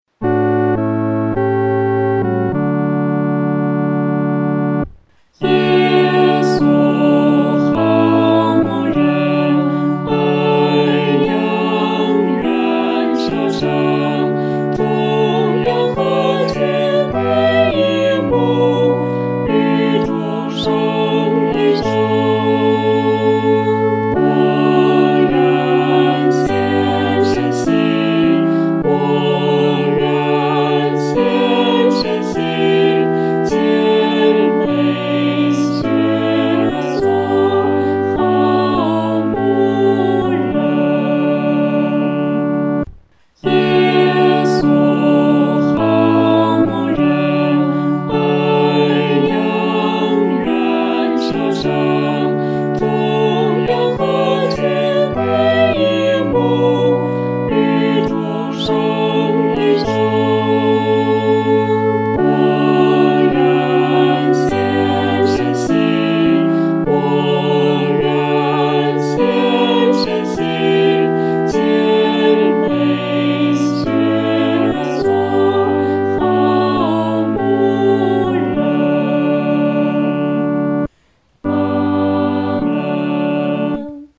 合唱
当歌词介绍“好牧人”的形像时曲调由平铺逐渐发展，趋向高潮。